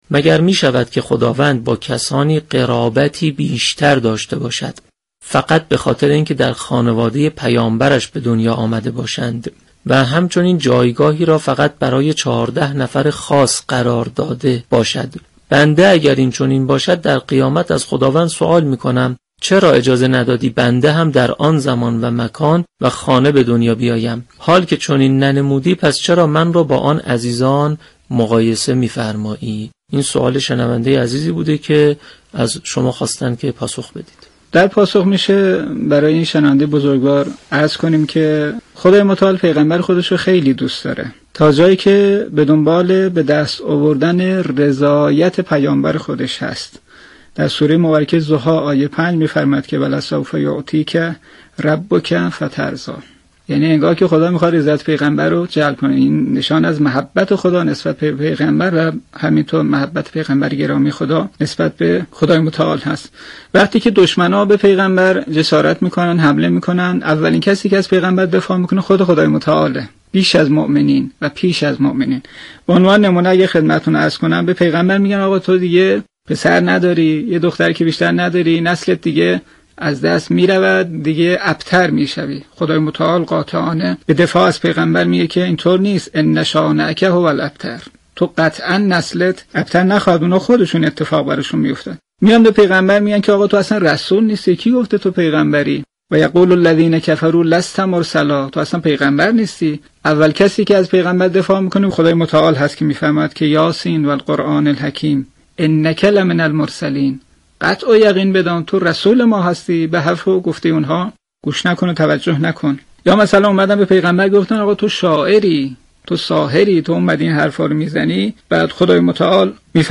گفتنی است ، در برنامه شمیم ظهور جمعه 14 آبان ماه كارشناس برنامه به پاسخ و گفت و گو پیرامون سوال "مگر می شود كه خداوند با كسانی قرابتی بیشر داشته باشند ، فقط بخاطر اینكه خانواده پیامبر(ص) هستند ؟" پرداخت.